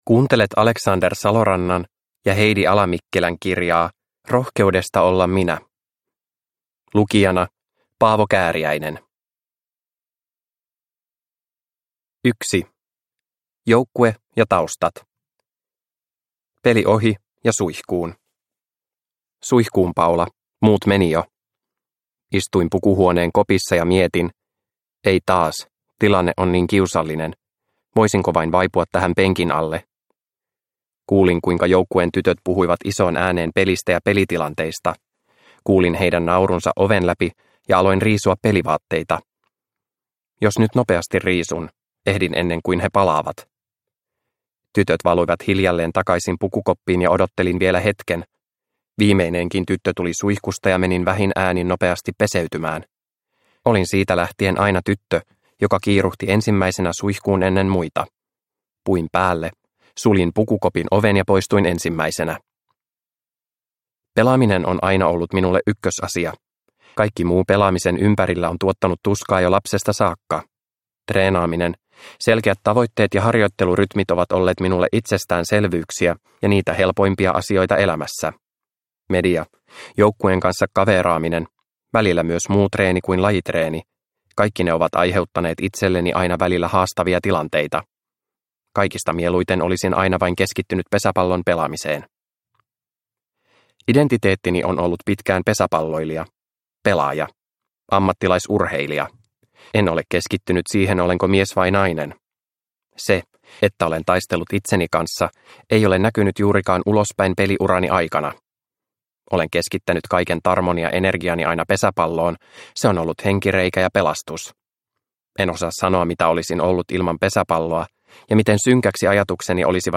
Rohkeudesta olla minä – Ljudbok – Laddas ner